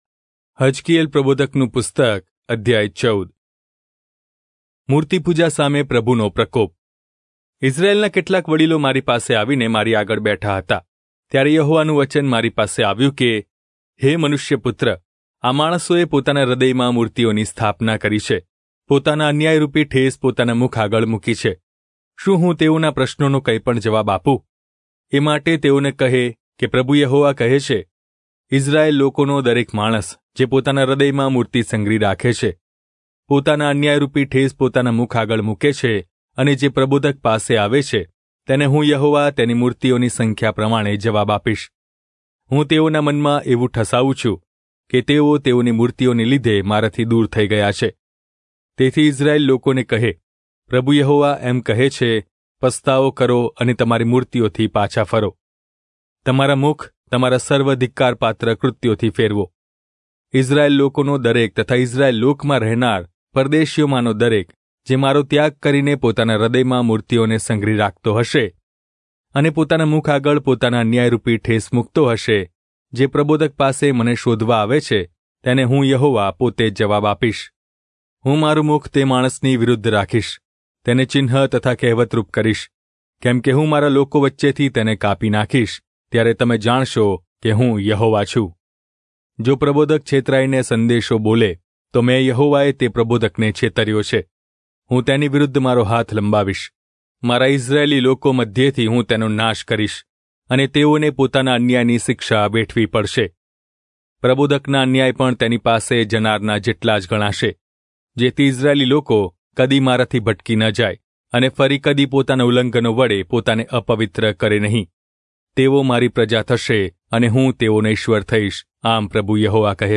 Gujarati Audio Bible - Ezekiel 13 in Irvgu bible version